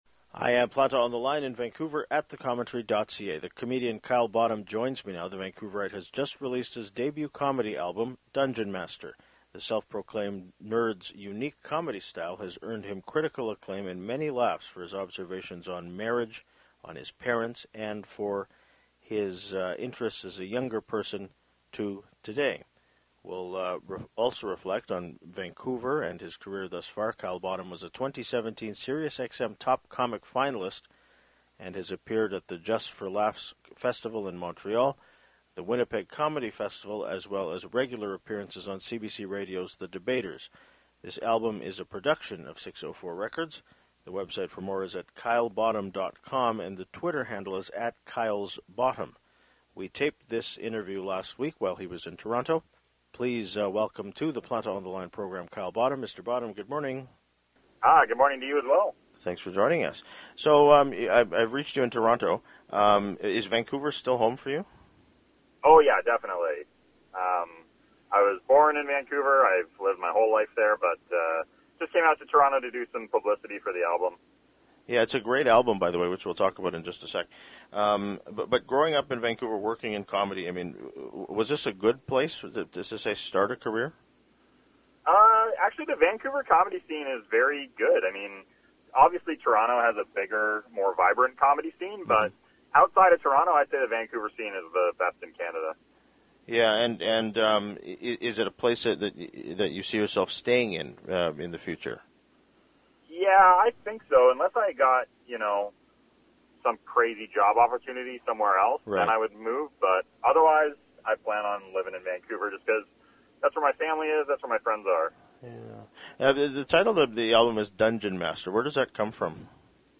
We taped this interview last week, while he was in Toronto.